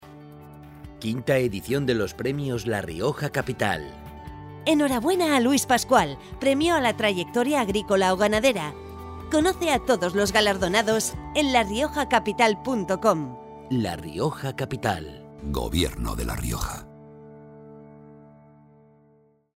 Versión 1 de cuña de 20".